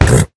sounds / mob / horse / hit3.mp3